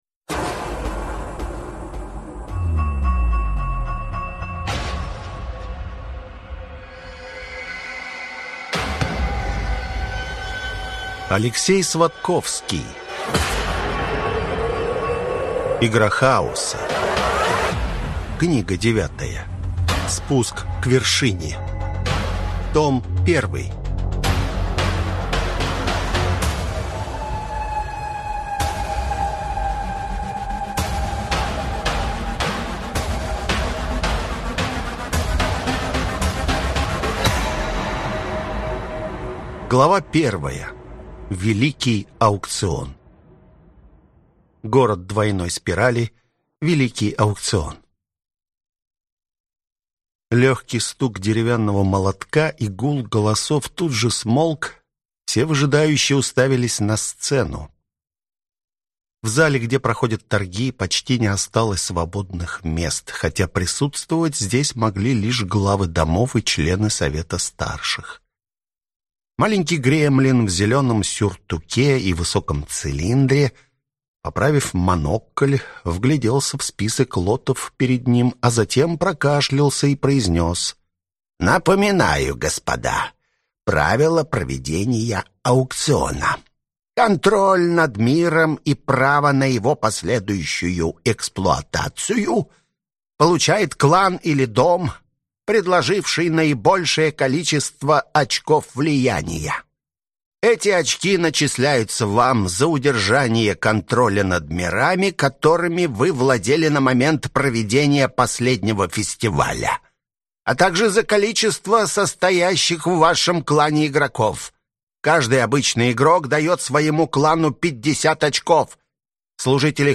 Аудиокнига Спуск к вершине. Том 1 | Библиотека аудиокниг